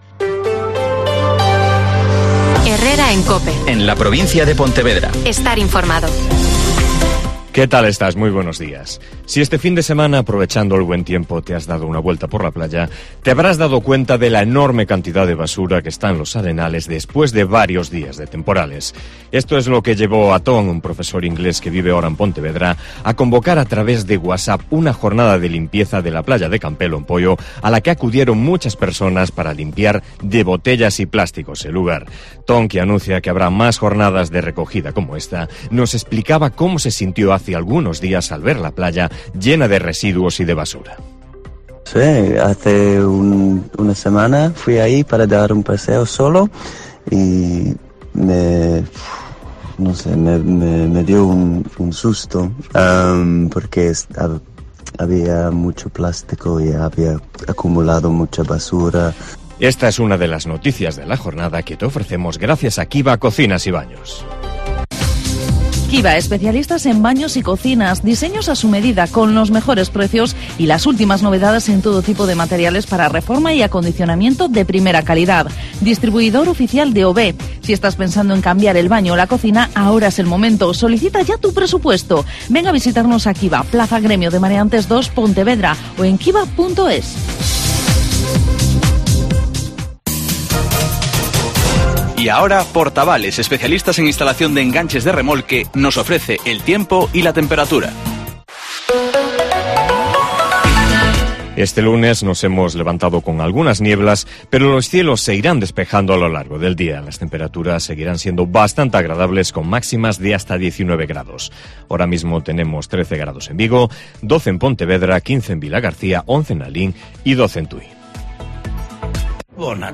Herrera en COPE Pontevedra y COPE Ría de Arosa (Informativo 08:24h)